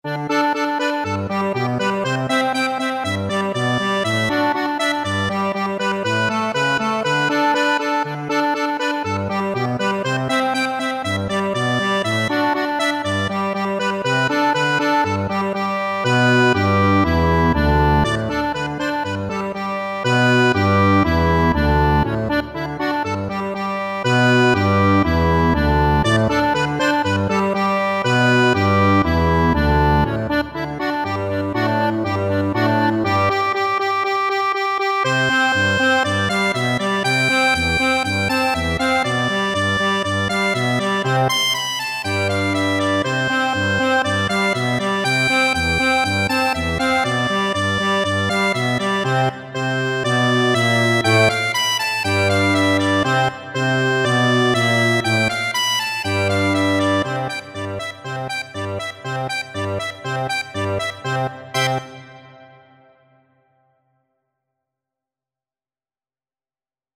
Classical Offenbach, Jacques Can Can Accordion version
4/4 (View more 4/4 Music)
G major (Sounding Pitch) (View more G major Music for Accordion )
Allegro = 120 (View more music marked Allegro)
Accordion  (View more Intermediate Accordion Music)
Classical (View more Classical Accordion Music)